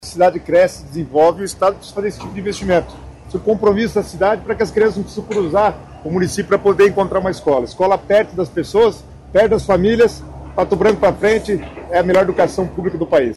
Sonora do secretário Estadual das Cidades, Guto Silva, sobre a obra de uma nova escola estadual no bairro Fraron, em Pato Branco